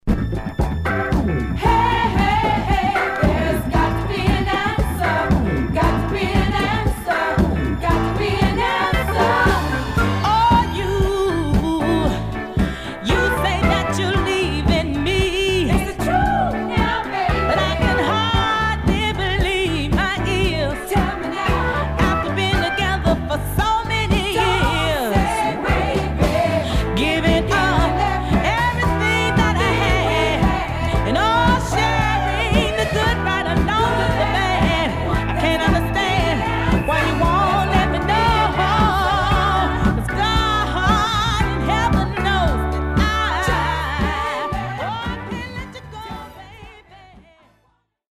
Mono
Folk